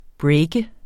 breake verbum Bøjning -r, -de, -t Udtale [ ˈbɹεjgə ] Oprindelse fra engelsk break 'gennembryde, afbryde' Betydninger 1.